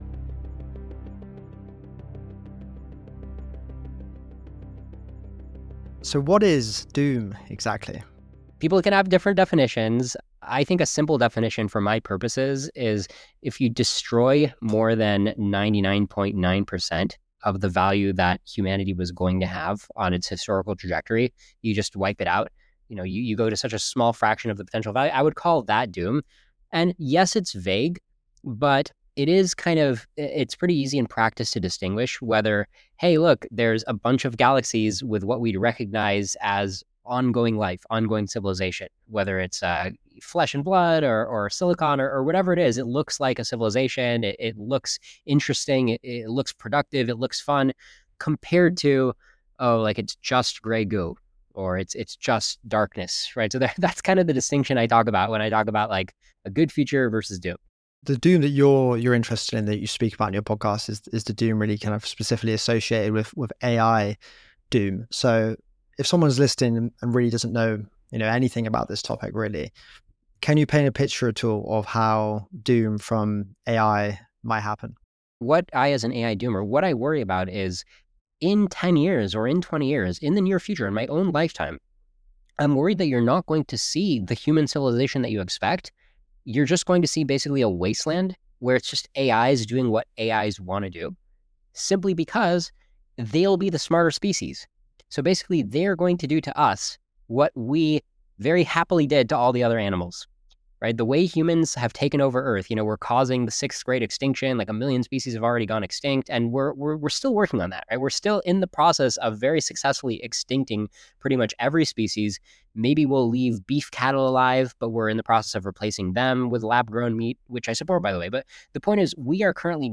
New interviews every week!